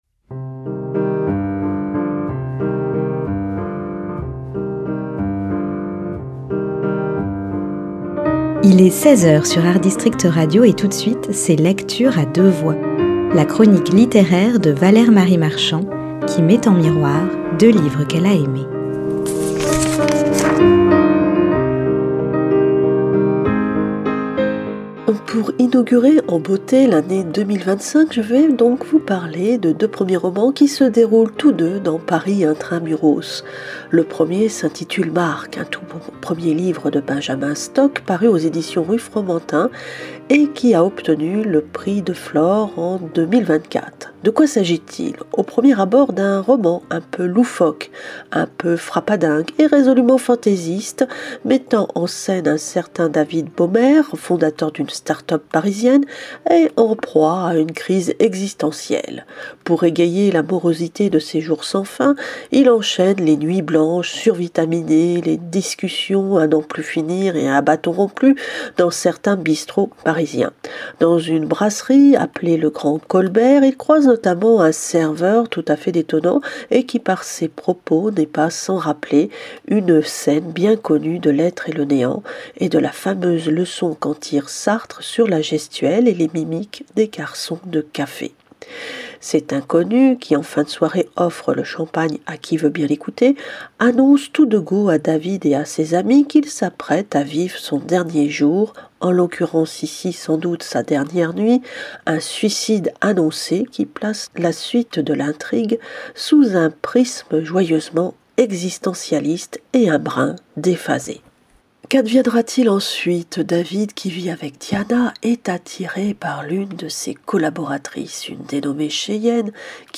LECTURE A DEUX VOIX, mardi et vendredi à 10h et 16h.